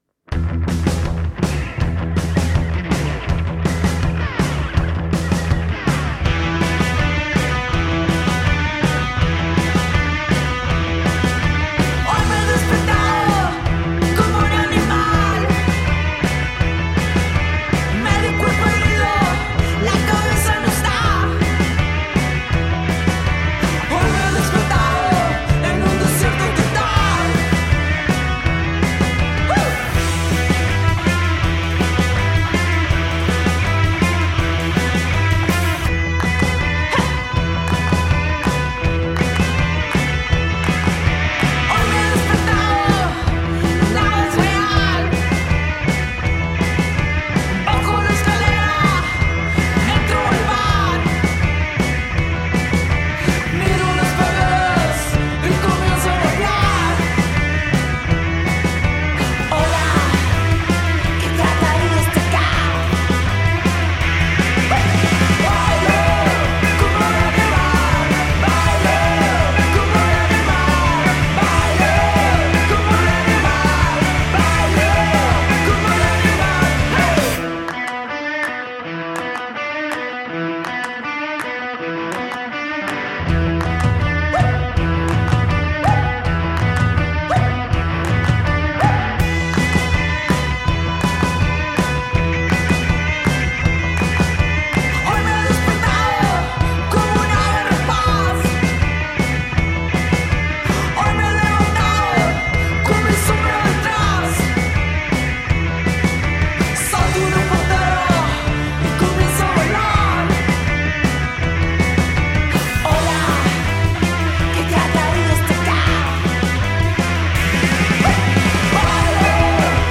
a bailar locamente